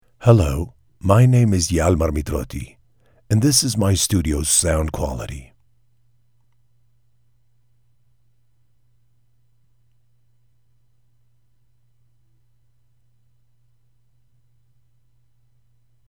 Male
Authoritative, Character, Conversational, Deep, Gravitas, Versatile, Warm
DEMO REEL ENG:SPA:FR 25.mp3
Audio equipment: professional sound proof studio